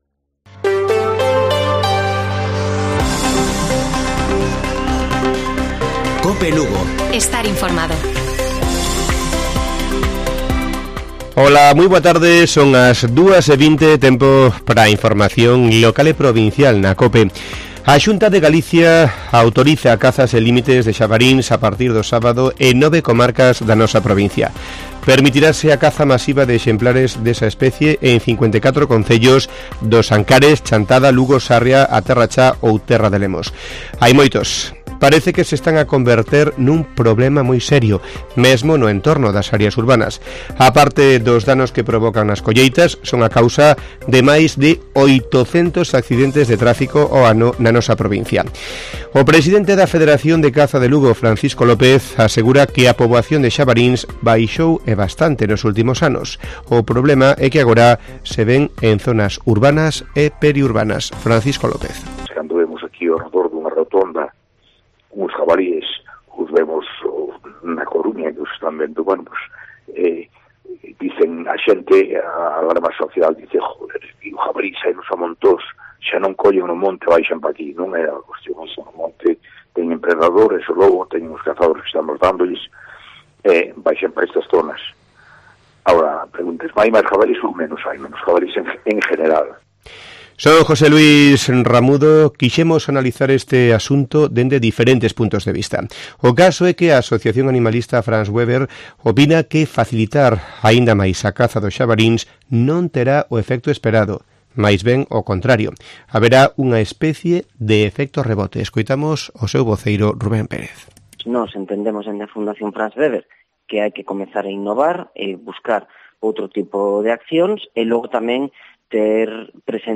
Informativo Mediodía de Cope Lugo. 14 DE SEPTIEMBRE. 14:20 horas